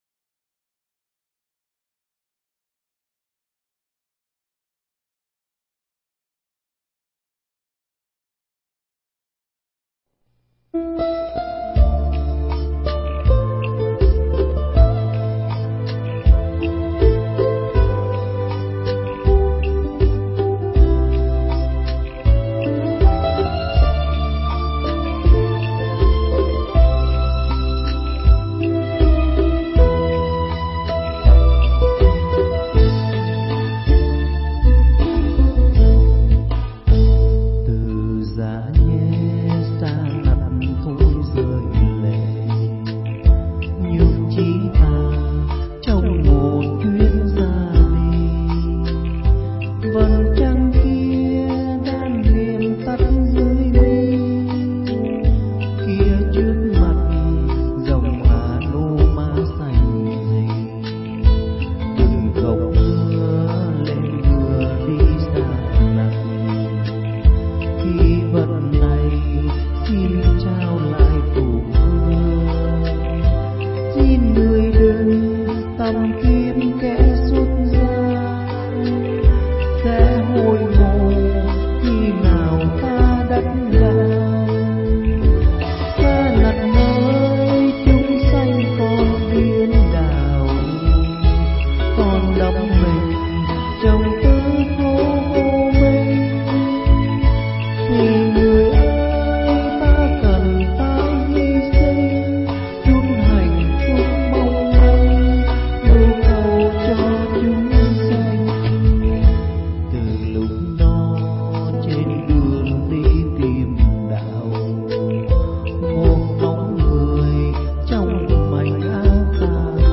thuyết giảng tại chùa Hải Đức, Canada